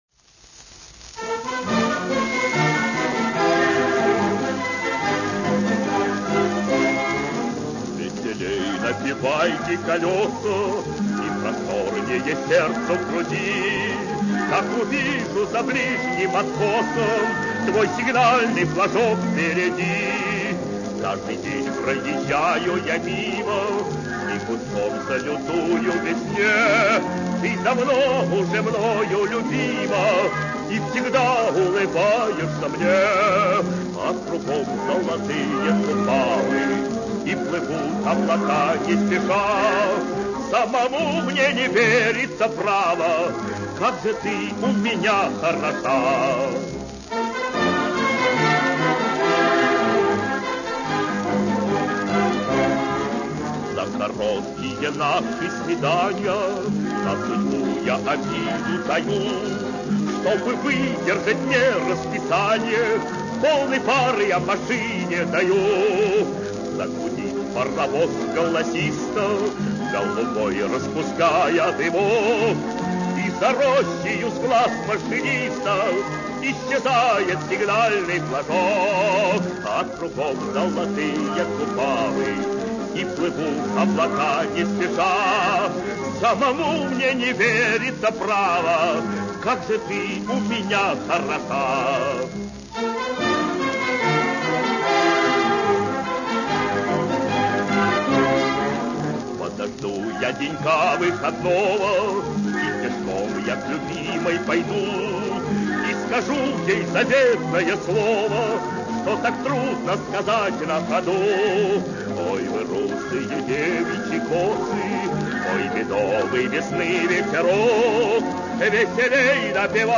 Красивая лирическая песня о железнодорожниках